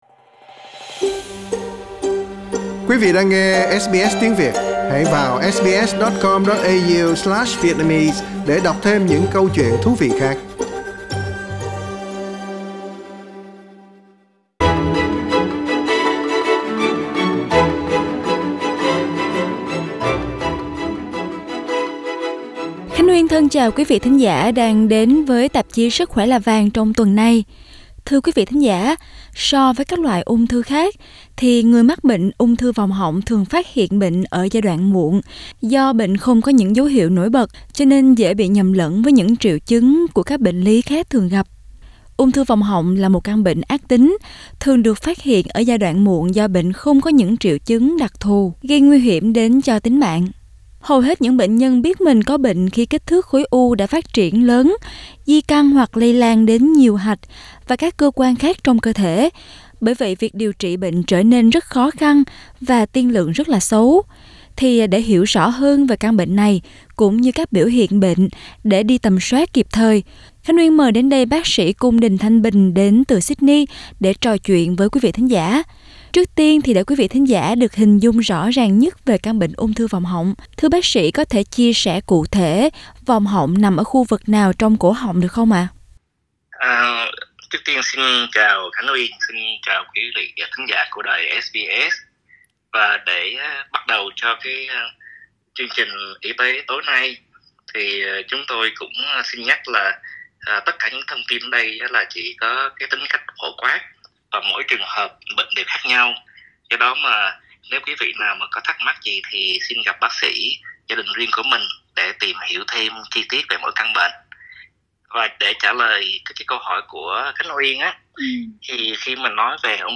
Nghe nguyên văn cuộc phỏng vấn trong phần radio.